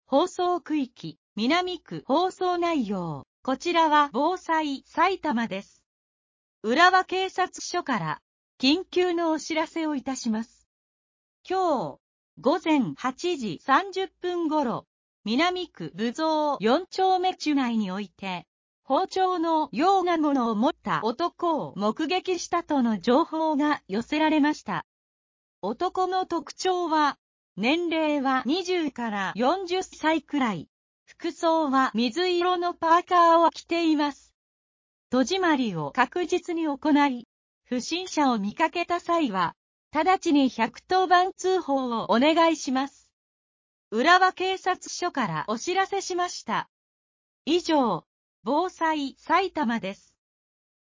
警察からの緊急放送 | 埼玉県さいたま市メール配信サービス